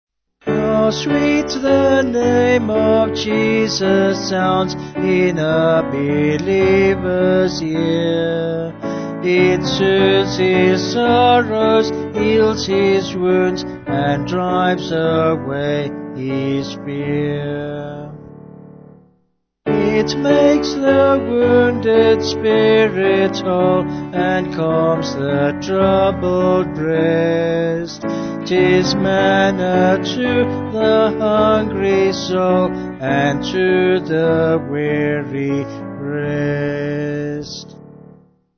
Vocals and Piano